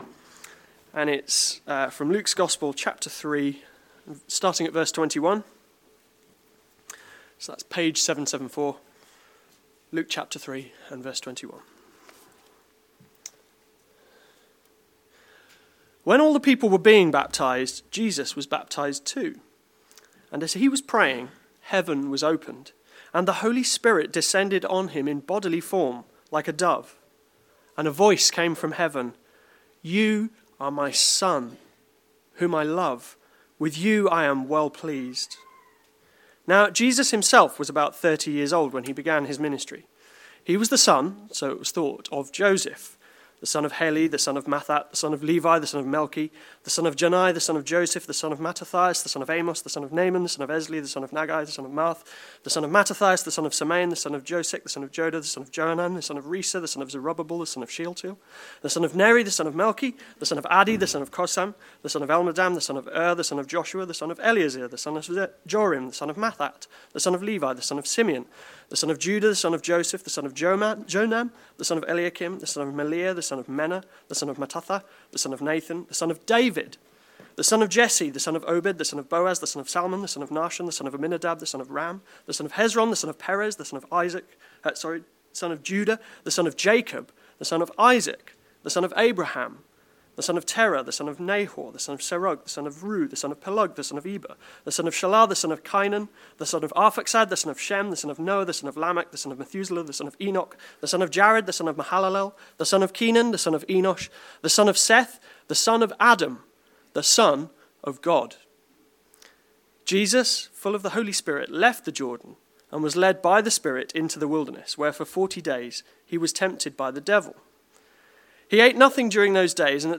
Sermon Video